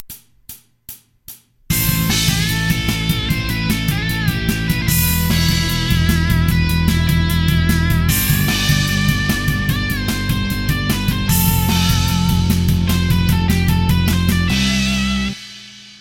Jam Track for Practicing Your Bending Technique
You can start off by learning this short solo before you inject your own riffs and creativity to change things up.